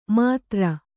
♪ mātra